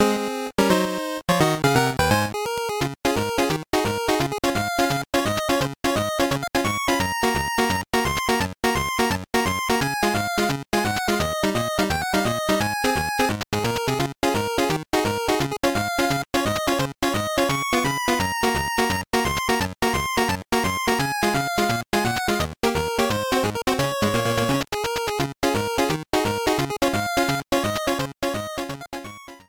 Trimmed to 30 seconds and applied fade-out when needed